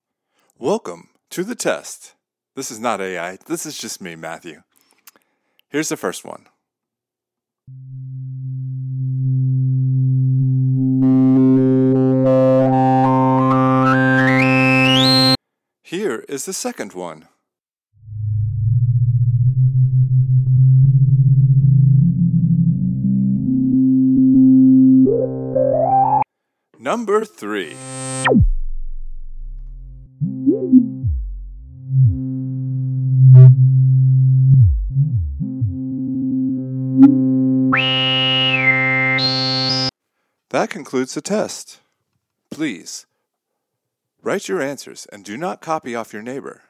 Here's a Filter Demo between:
• Free iOS/iPad App, Synth One J6
• $200 VST by a company starting with "R"
• $1800 Vintage refurbished Juno
(All 3 sound clips are Init patches, C2 & C3 held, No Chorus, Full Resonance)
FilterTest.mp3